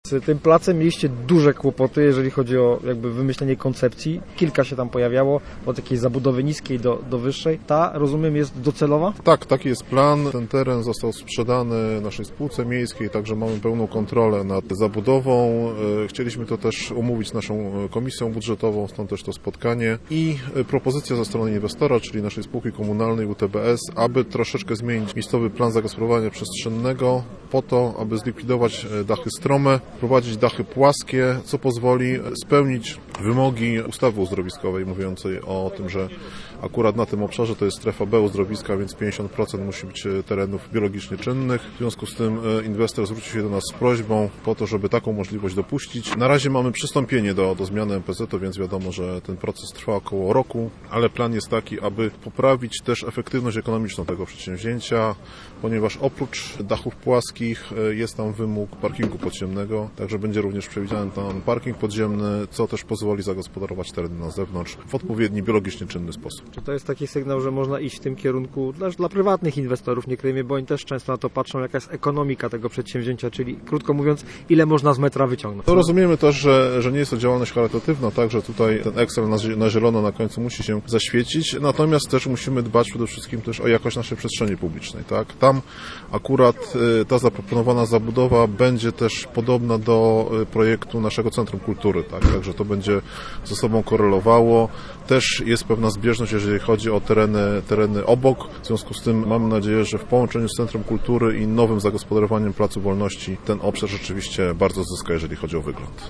Posłuchaj rozmowy z wiceburmistrzem Ustki: https